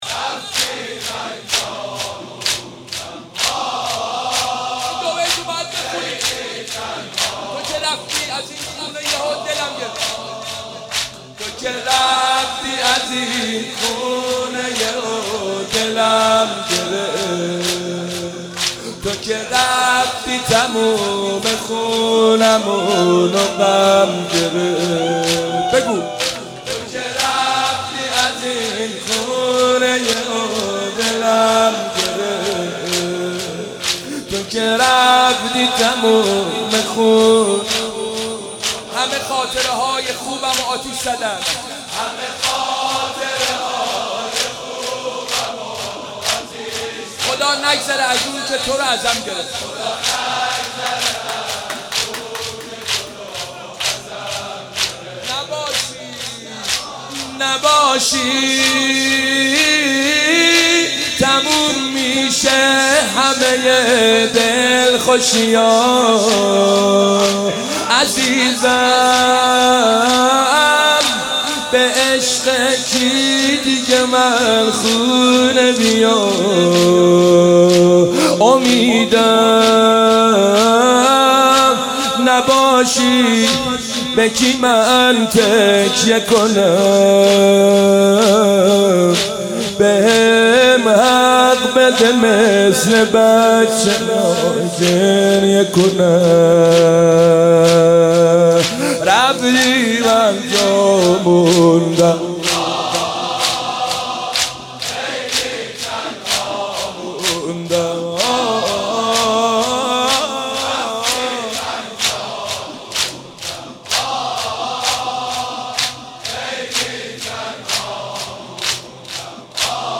مداحی
در هیأت روضة‌العباس(ع)